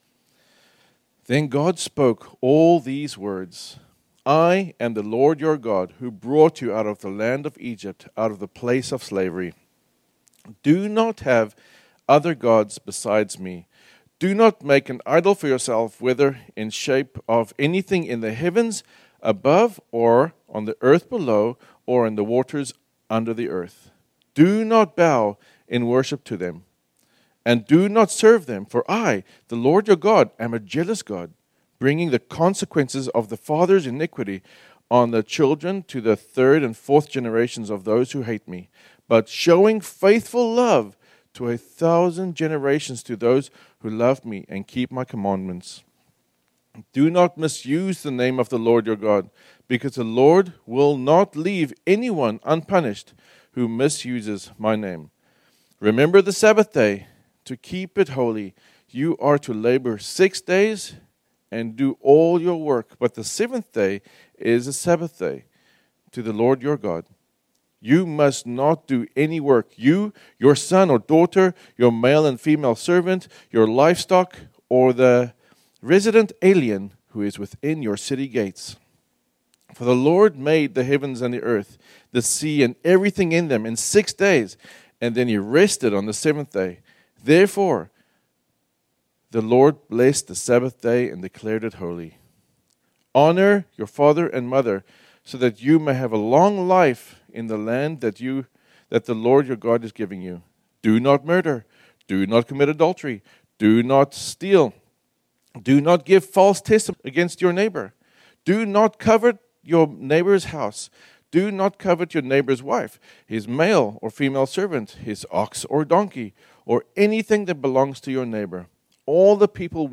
This sermon was originally preached on Sunday, March 23, 2025.